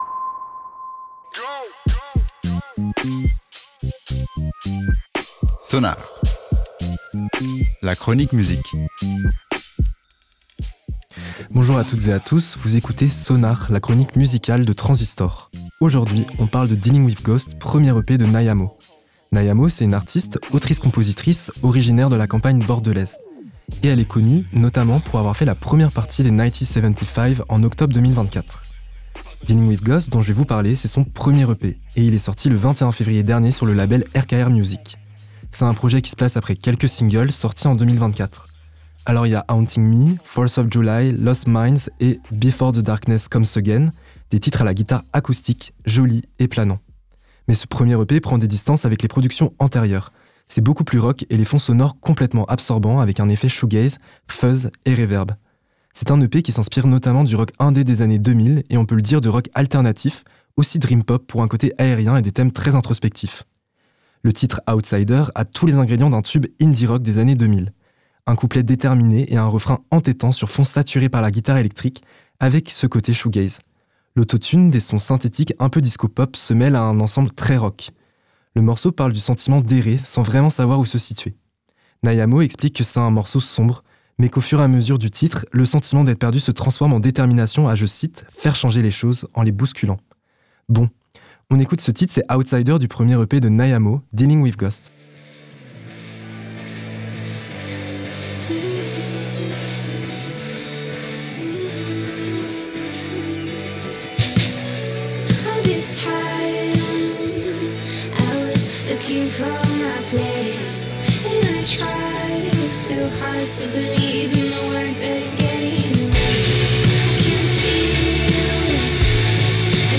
Ce projet arrive après quelques singles sortis en 2024, des titres planants à la guitare acoustique. Ce premier EP est lui plus rock, les fonds sonores absorbants avec des effets fuzz et reverb.